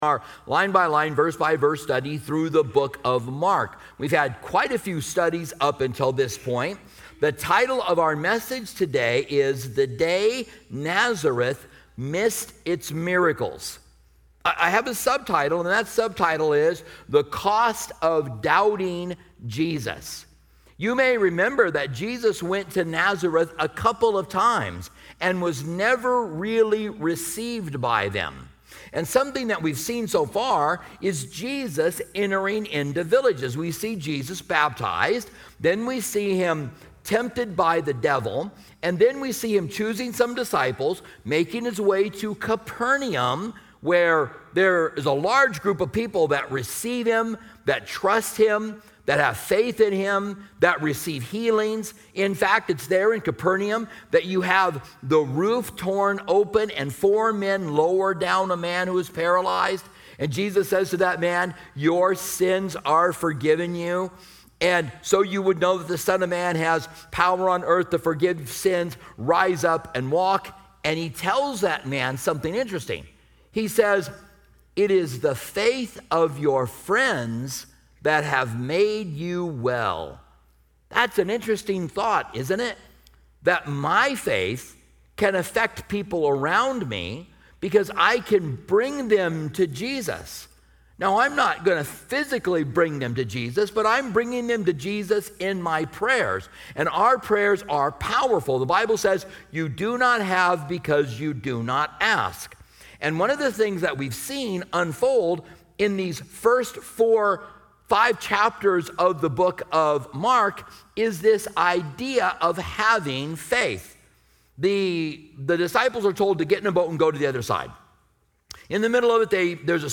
a teaching